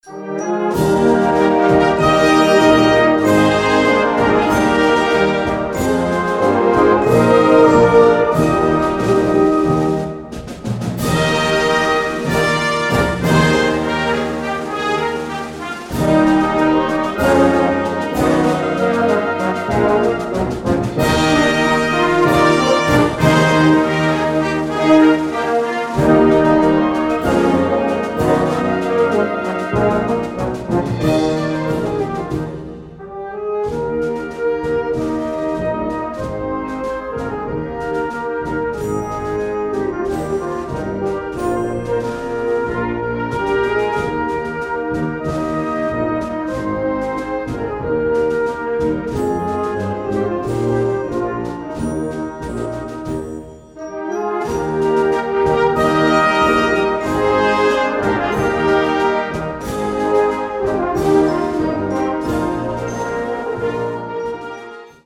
Category Concert/wind/brass band
Subcategory Opening music
Instrumentation Ha (concert/wind band)